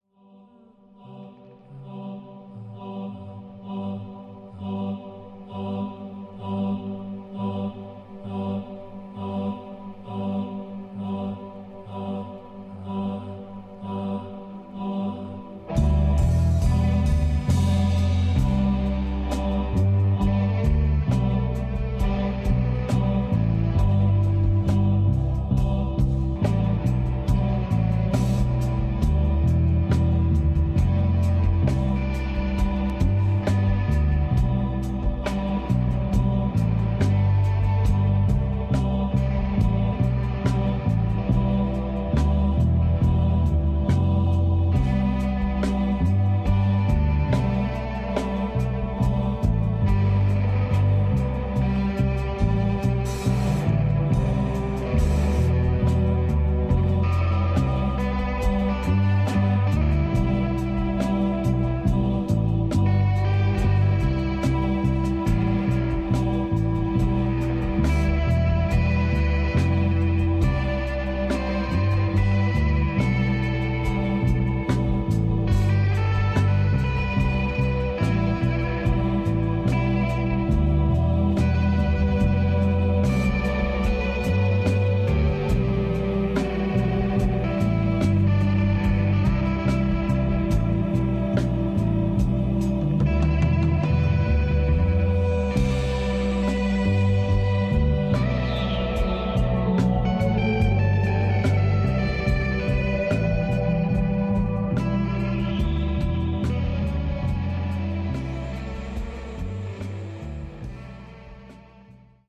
Tags: Rock , Psicodelico , Colombia , Bogotá